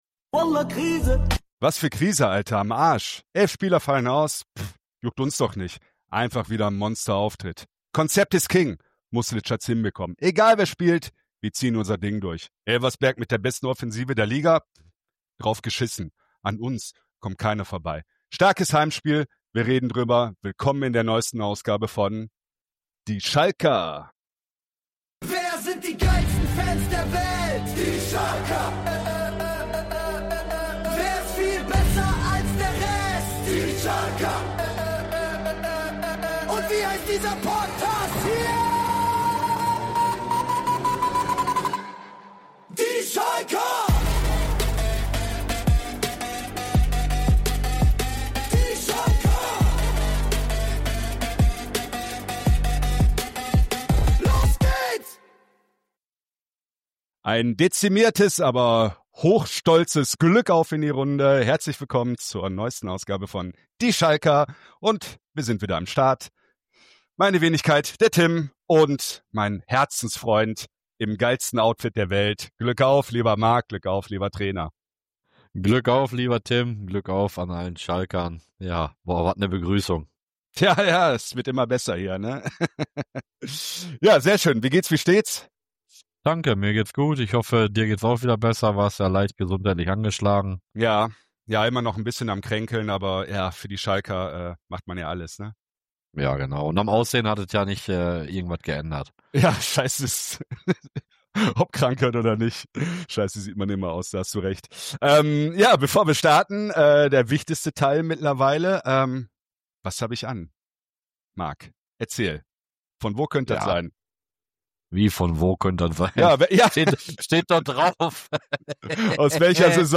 Dazu gibt’s Stimmen aus der Community, eine knackige Punkteprognose und natürlich: das legendäre Trikot der Folge von Dress04.
Nur zwei Typen, die Schalke fühlen.